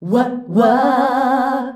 UAH-UAAH C.wav